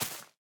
Minecraft Version Minecraft Version snapshot Latest Release | Latest Snapshot snapshot / assets / minecraft / sounds / block / moss / break4.ogg Compare With Compare With Latest Release | Latest Snapshot
break4.ogg